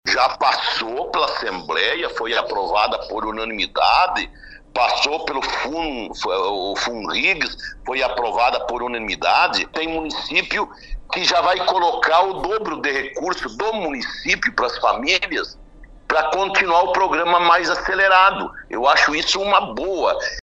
Ontem, às 6 horas e 30 minutos, durante entrevista no programa Progresso Rural da RPI, o secretário de Desenvolvimento Rural do Rio Grande do Sul, Vilson Covati, disse que o operação Terra Forte vai seguir, pois é um programa de Estado e os municípios também podem participar com recursos e aumentar o número de agricultores abrangidos.